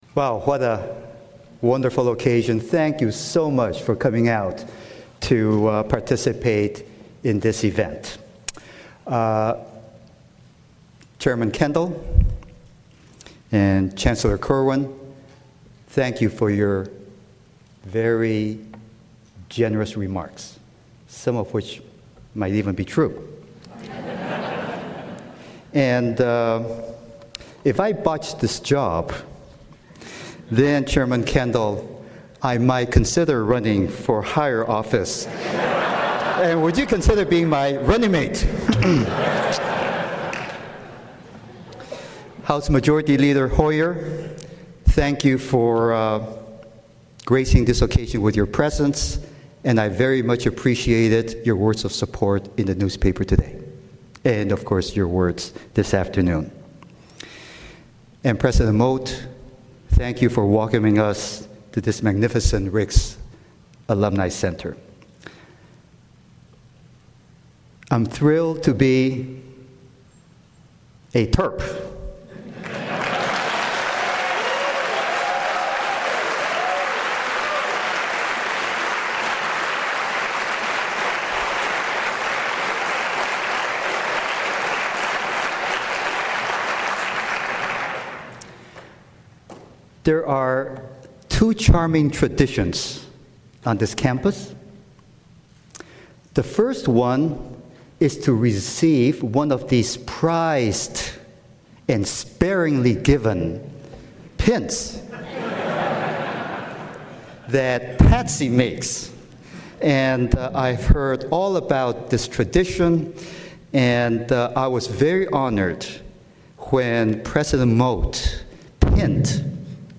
Welcoming Ceremony in the Riggs Alumni Center